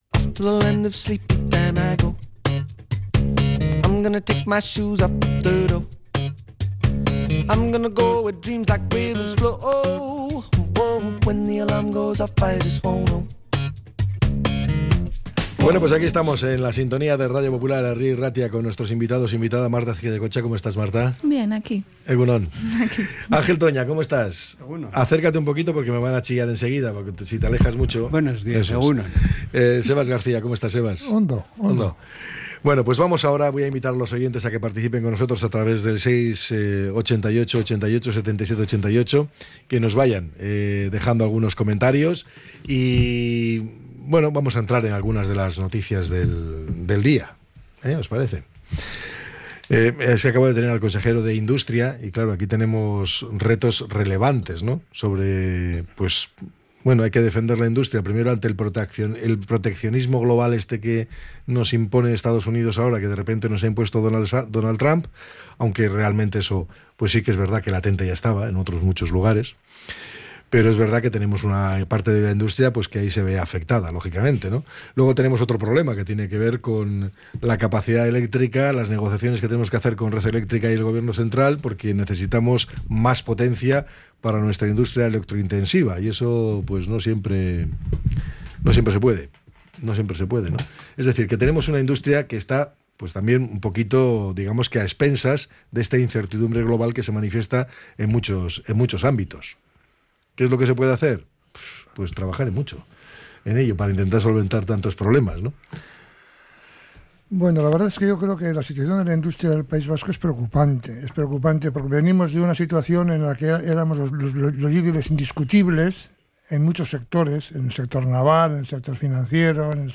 La tertulia 21-05-25.